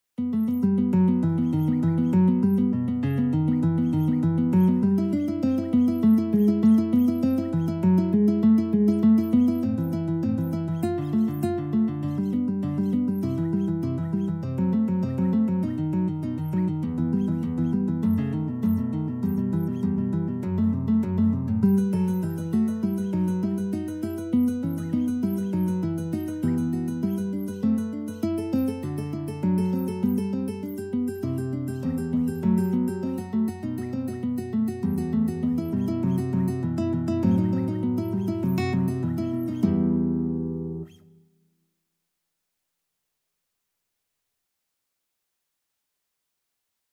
4/4 (View more 4/4 Music)
Classical (View more Classical Guitar Duet Music)